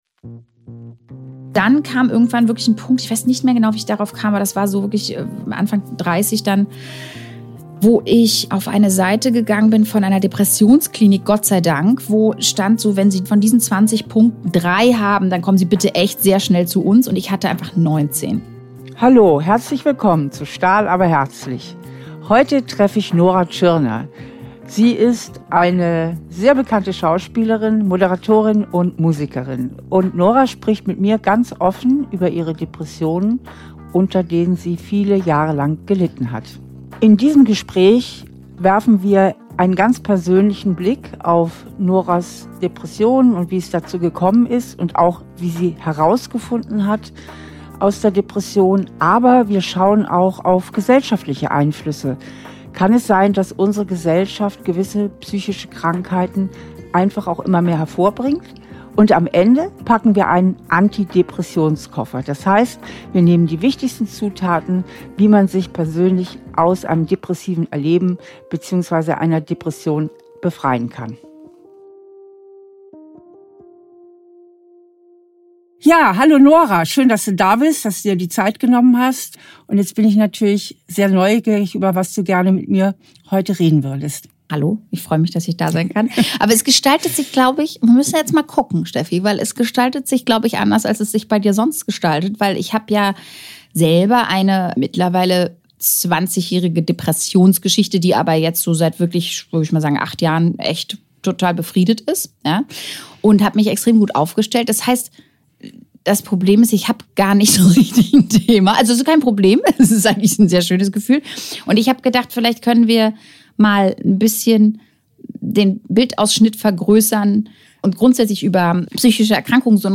Trotz des Erfolgs litt sie lange Zeit an Depressionen und einem tiefen Gefühl der Einsamkeit. Mit Stefanie Stahl spricht sie über den enormen Druck, dem wir in unserer Leistungsgesellschaft ausgesetzt sind, und wie sie sich Stück für Stück aus ihrer Depression befreien konnte.